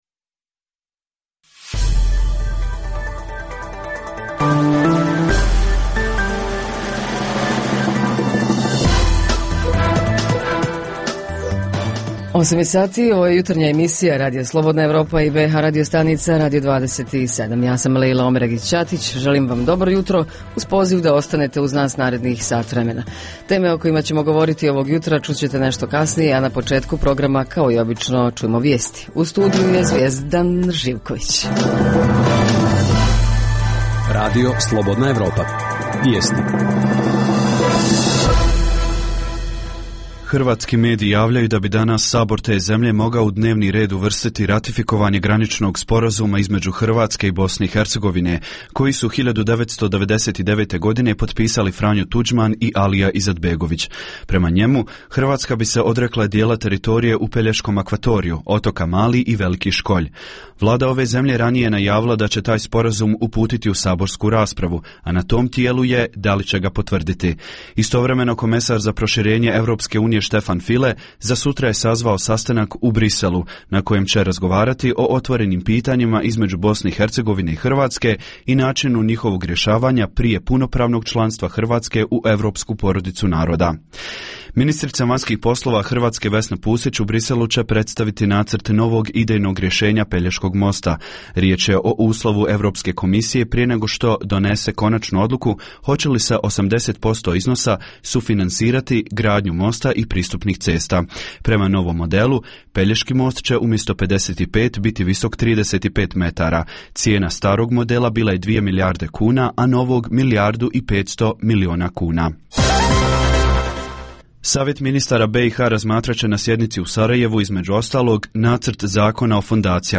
Baš kao da apsurd, primitivizam, odsustvo zdravog razuma i samoponiženje u BiH nemaju granica. Tema jutra: Pripreme uoči lokalnih izbora u BiH (Edukacija članova biračkih odbora, imenovanje posmatrača itd) Više o tome čućemo od dopisnika iz Brčkog, Doboj Juga, Prijedora i Jablanice.